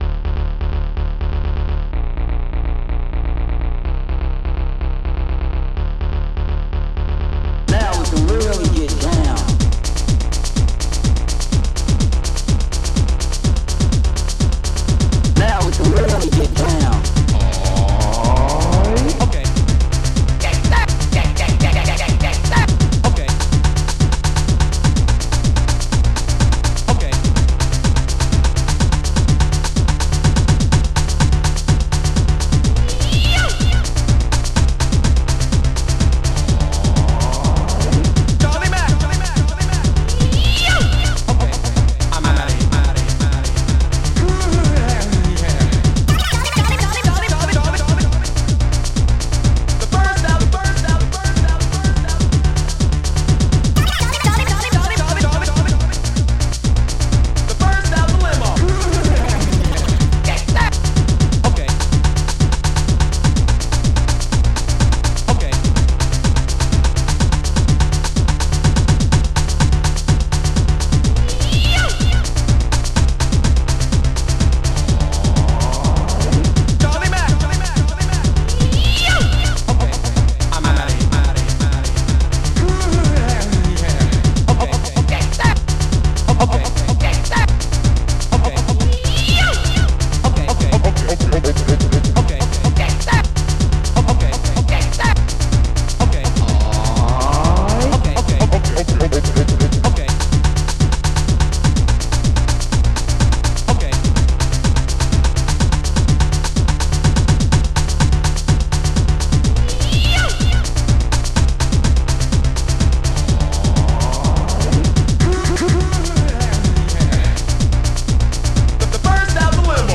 SoundTracker Module  |  1992-12-05  |  188KB  |  2 channels  |  44,100 sample rate  |  2 minutes, 25 seconds
st-10:express-snare
st-05:cyd-bass1
st-09:bassdrum-duim
st-10:claps1